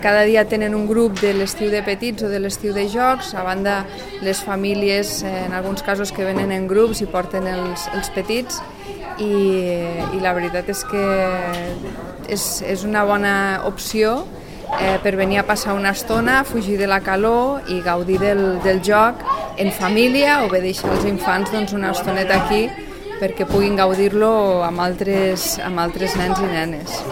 tall-de-veu-de-lalcaldessa-accidental-sandra-castro-sobre-el-servei-destiu-de-les-ludoteques-municipals